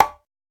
washboard_e.ogg